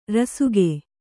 ♪ rasuge